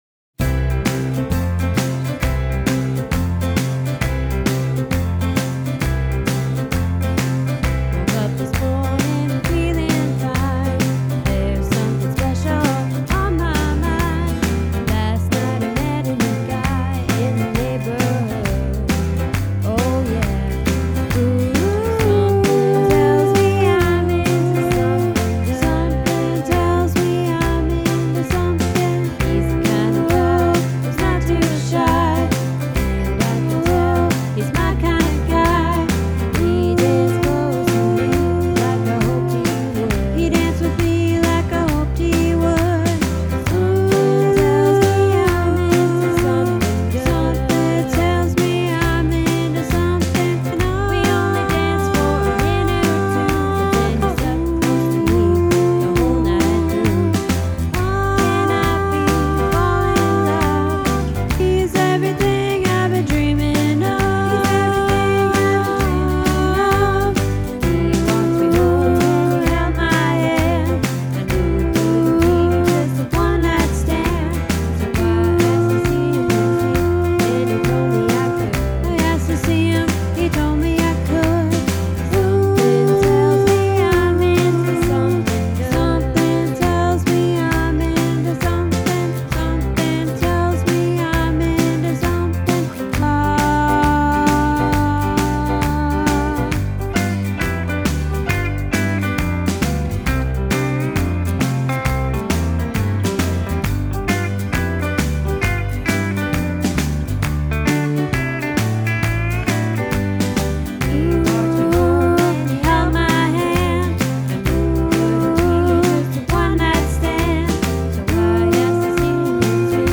Into Something Good - Tenor(louder)